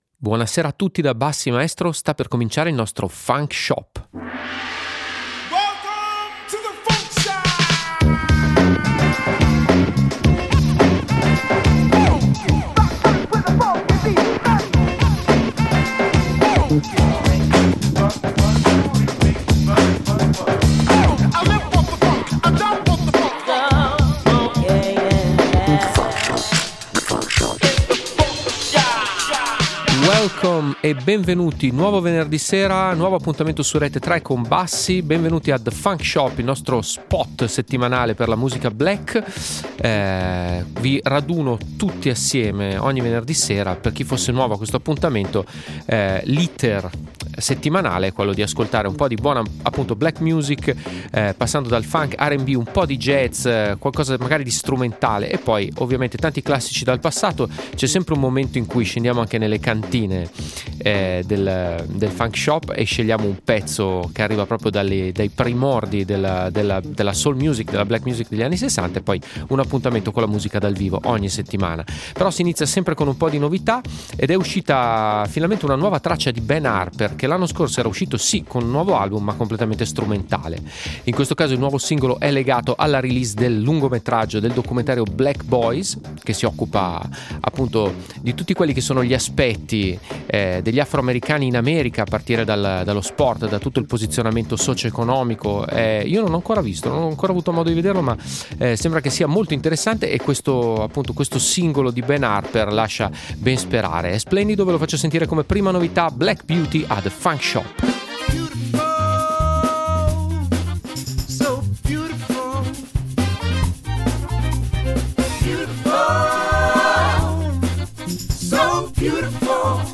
RAP / HIP-HOP BLACK